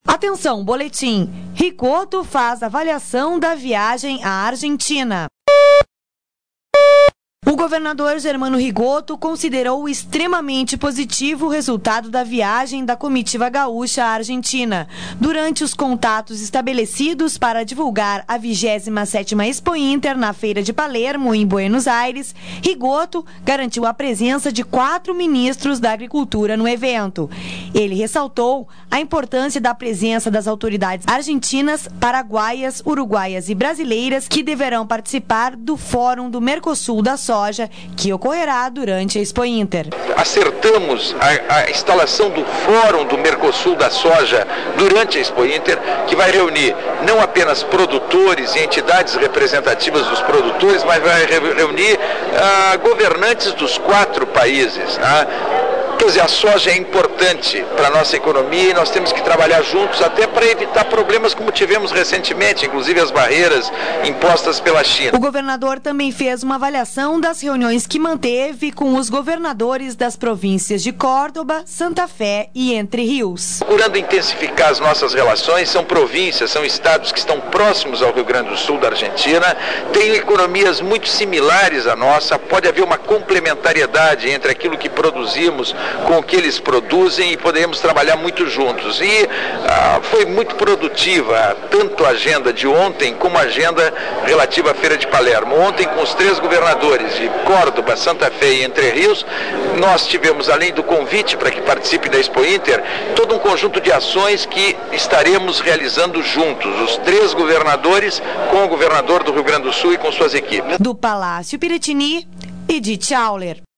O governador Germano Rigotto fez uma avaliação sobre a viagem à Argentina. Ele destacou os contatos estabelecidos com os governadores das províncias de Córdoba, Santa Fé e Entre Rios. Sonora: Governador do Estado, Germano Rigotto Local: Porto Alegre -